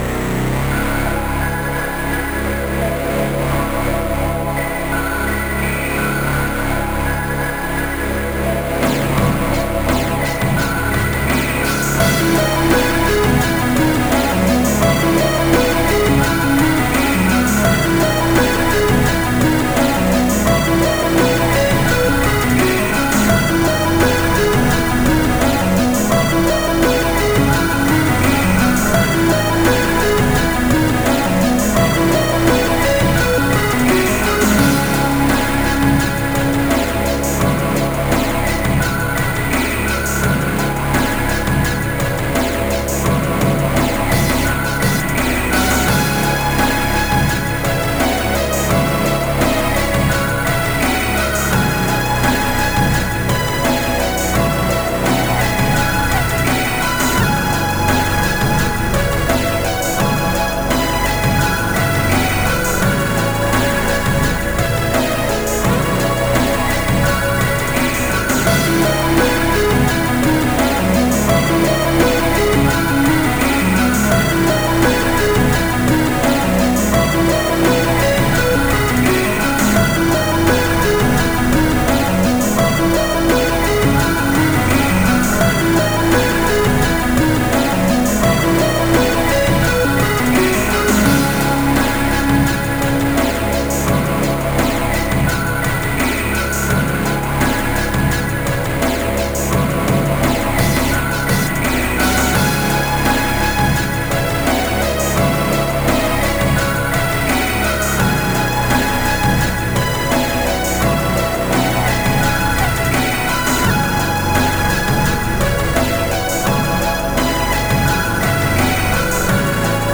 3:01 Style: Synthpop Released